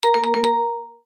timer.mp3